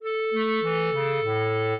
minuet7-6.wav